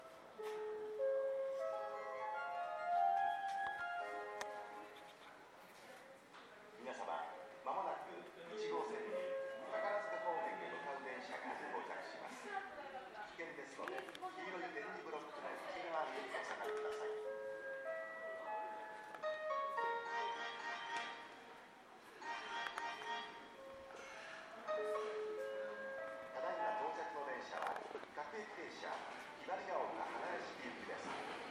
この駅では接近放送が設置されています。
接近放送急行　宝塚行き接近放送です。